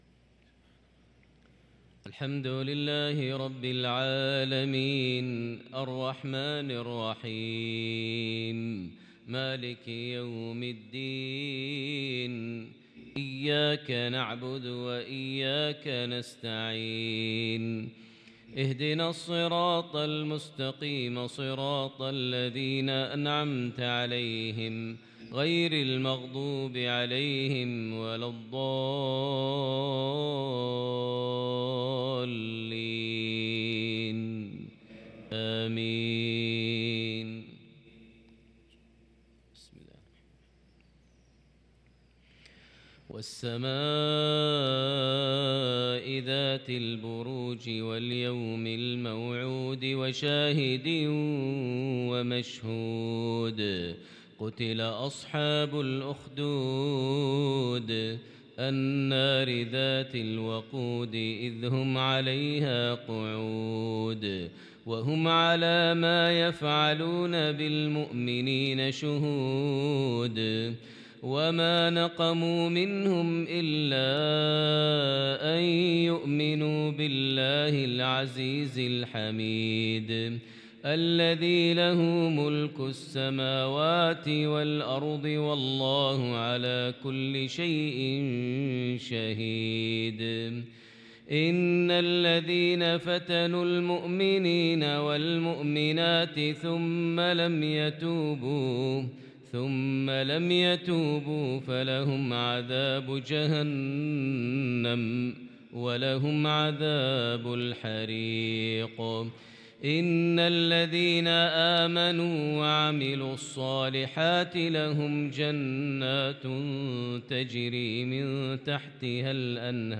صلاة المغرب للقارئ ماهر المعيقلي 12 شوال 1443 هـ